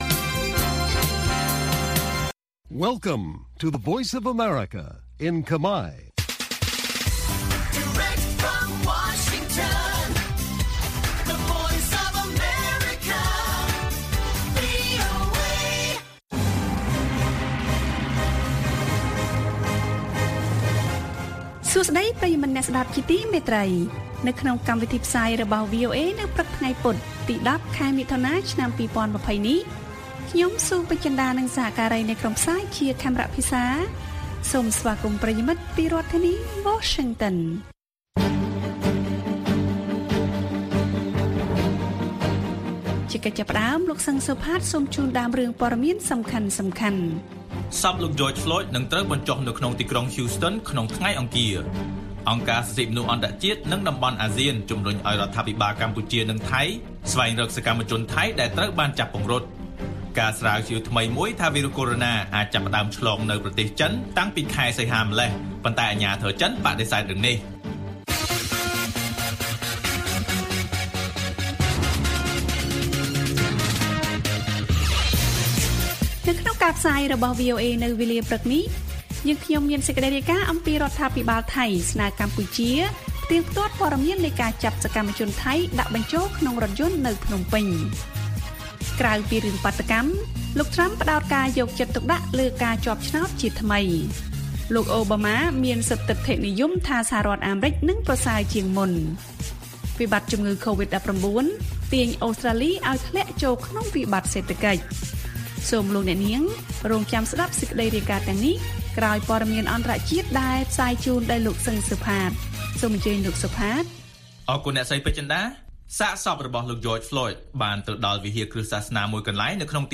ព័ត៌មានពេលព្រឹក